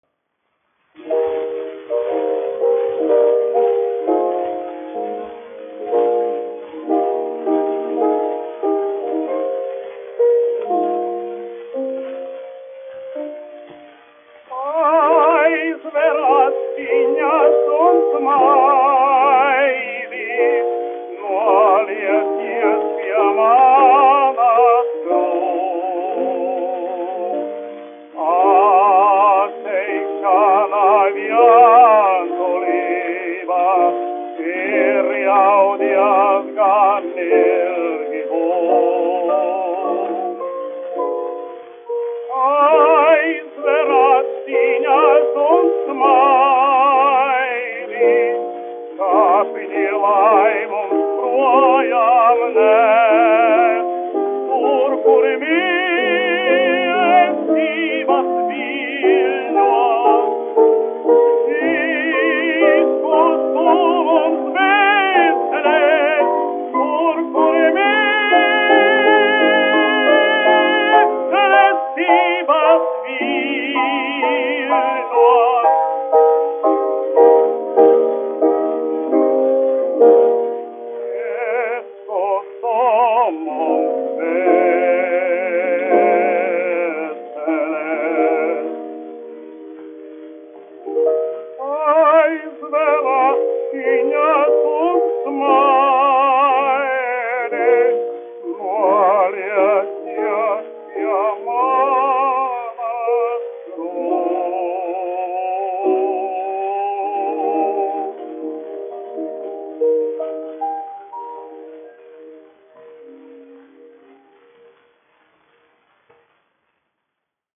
1 skpl. : analogs, 78 apgr/min, mono ; 25 cm
Dziesmas (augsta balss) ar klavierēm
Skaņuplate
Latvijas vēsturiskie šellaka skaņuplašu ieraksti (Kolekcija)